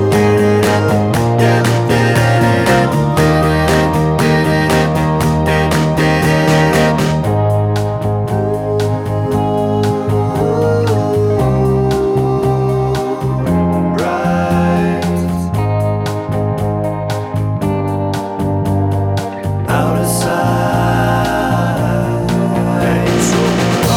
Minus Lead Guitar Pop (1960s) 2:35 Buy £1.50